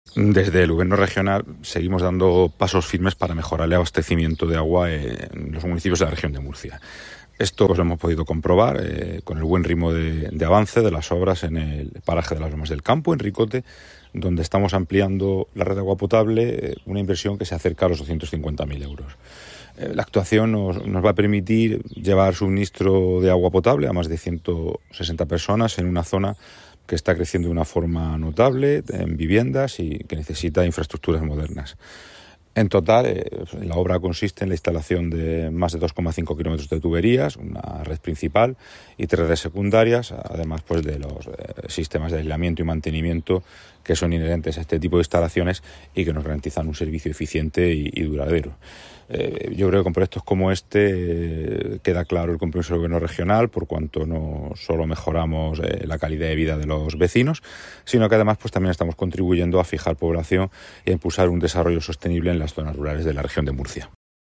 Vota: | Resultado: 3 votos Categoría nota prensa: Agua, Agricultura, Ganadería y Pesca Contenidos Asociados: Declaraciones del director general del Agua, José Sandoval, en las que detalla las actuaciones llevadas a cabo en Ricote.